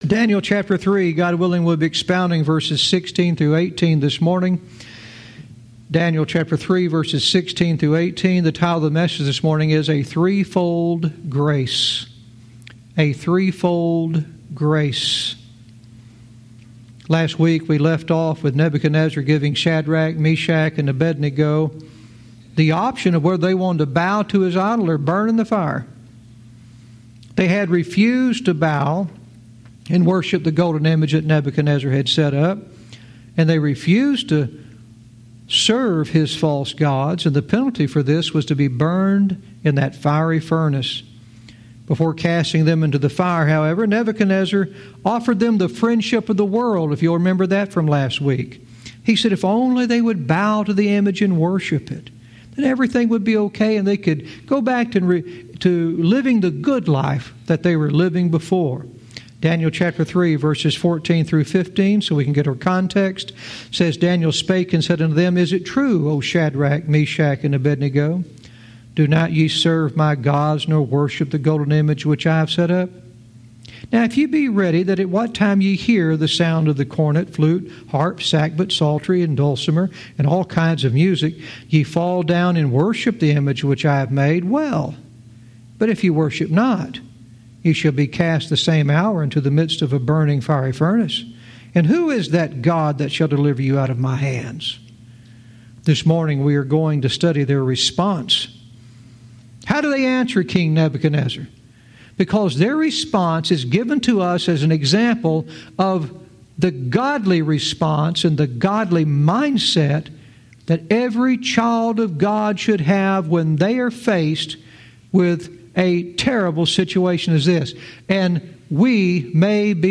Verse by verse teaching - Daniel 3:16-18 "A Threefold Grace"